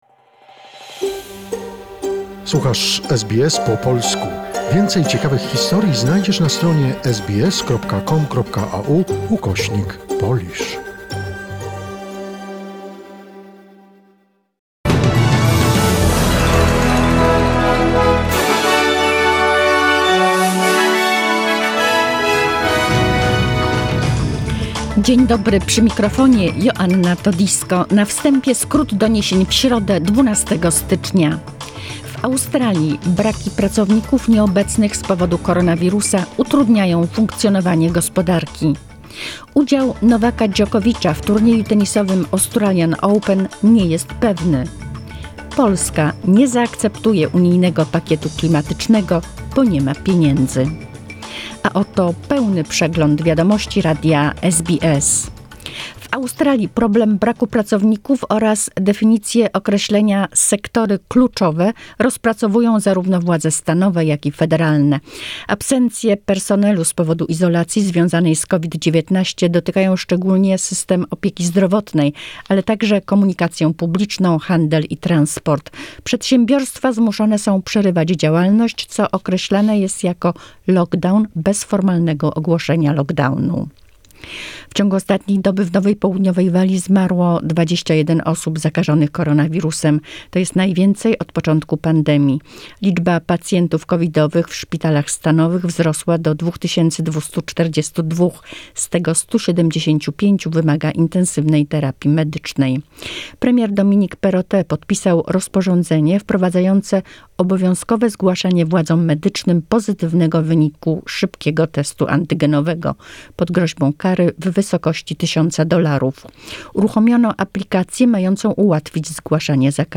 SBS News in Polish, 12 January 2022